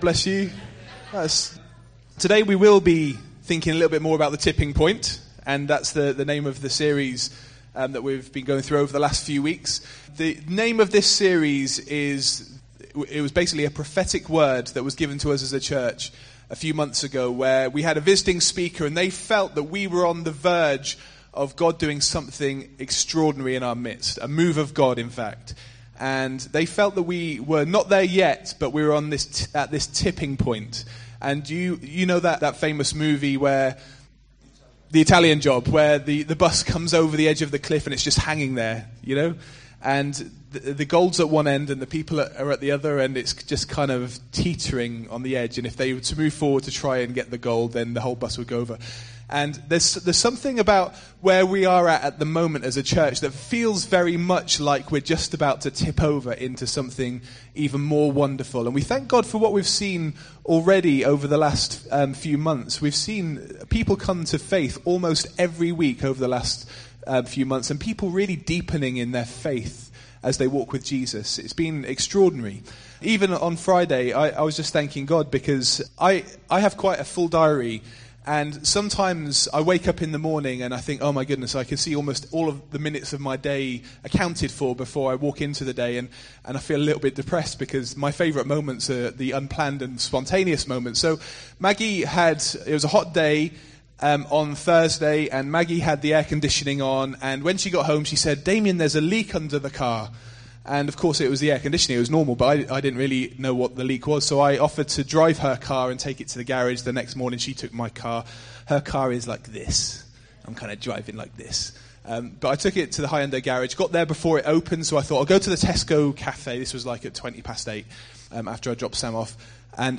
Notes Sermons in this Series Continuing the Tipping Point series